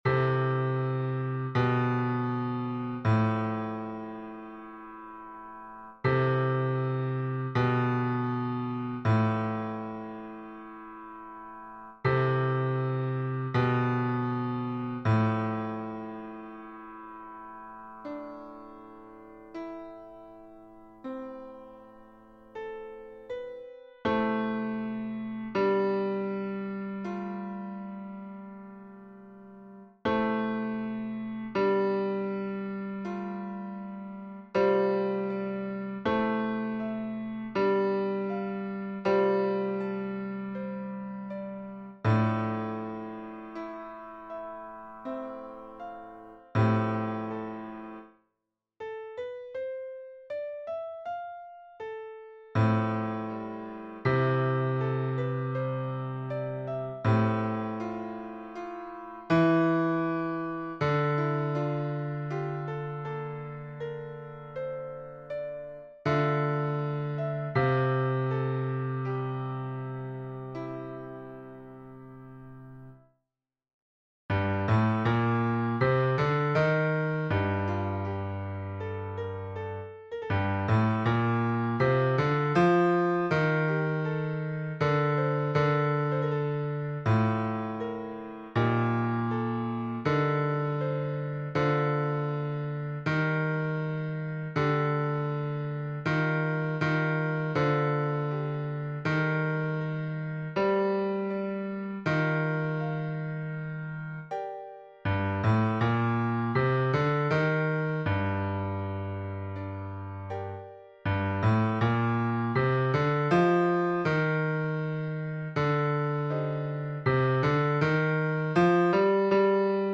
Baryton (version piano)